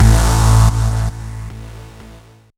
bseTTE52006hardcore-A.wav